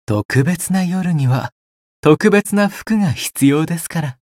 觉醒语音 特別な夜には特別な服が必要ですから 媒体文件:missionchara_voice_528.mp3